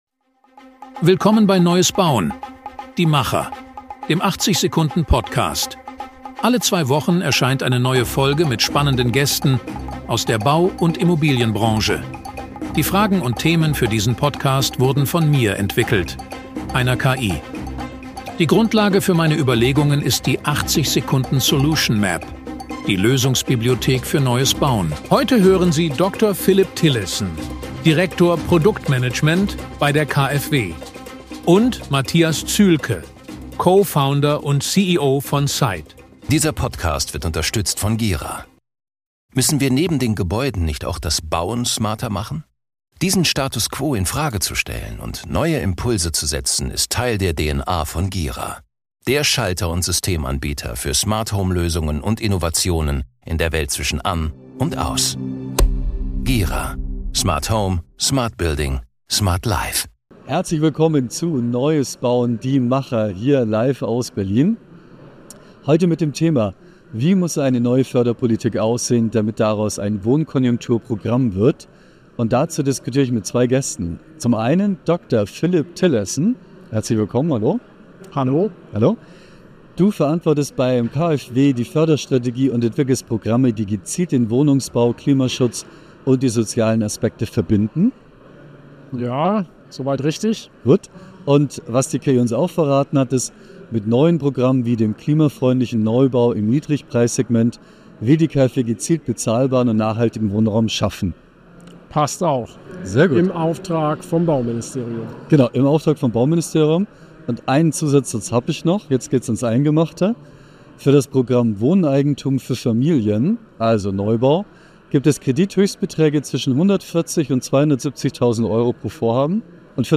Live aus Berlin vom Podcast Summit 2025: Wie muss eine neue Förderpolitik aussehen, damit daraus ein echtes Wohnkonjunktur-Programm wird?